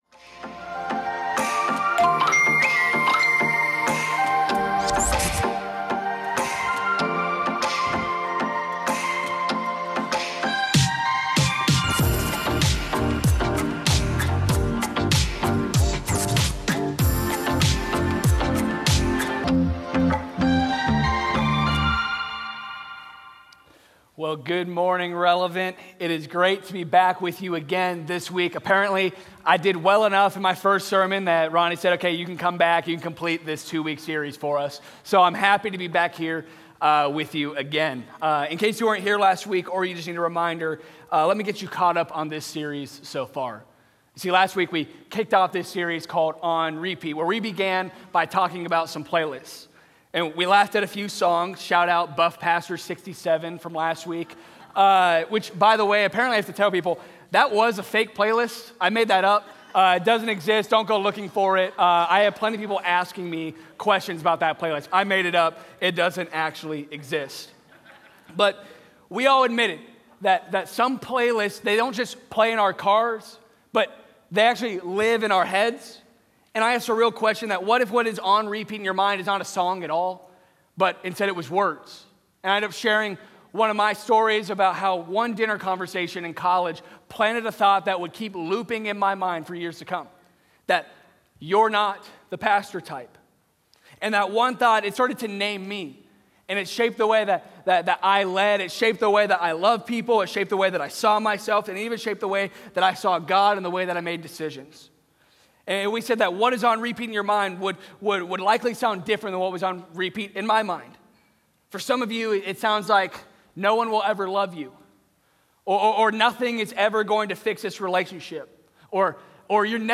Sunday Sermons On Repeat, Week 2: "Change the Track" Oct 19 2025 | 00:35:53 1x 00:00 / 00:35:53 Subscribe Share Apple Podcasts Spotify Overcast RSS Feed Share Link Embed